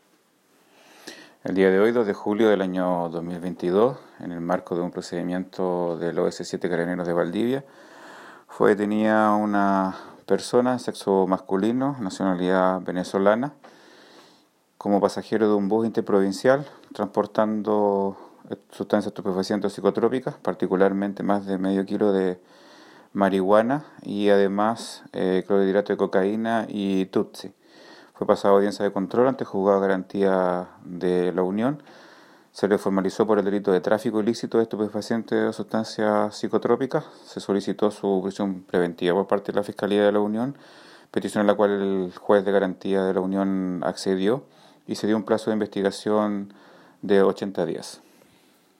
Fiscal Raúl Suárez.